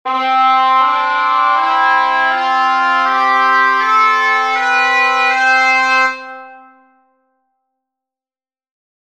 For the curious, I used Musescore2 to create these scale samples, due to it’s easy-to-apply tuning adjustments on notes, with the bagpipe sound specifically because it did not have vibrato.